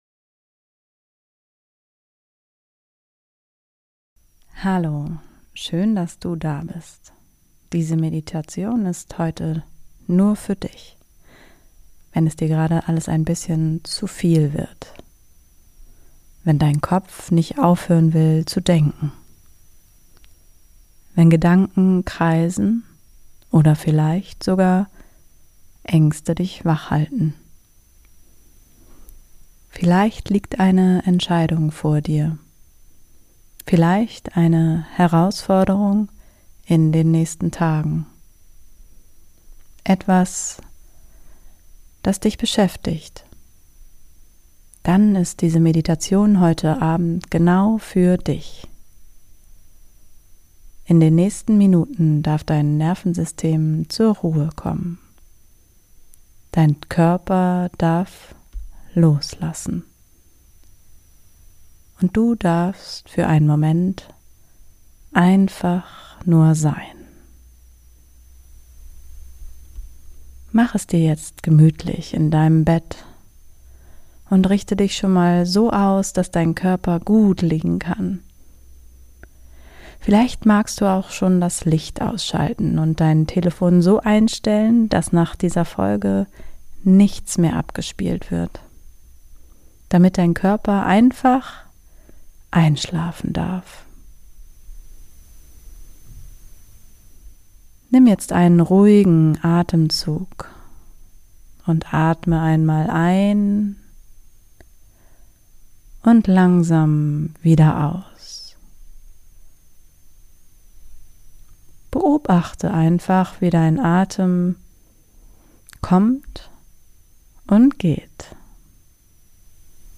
#048 Meditation zum Einschlafen ~ beyond BREATH - Raus aus dem Kopf, rein in den Körper Podcast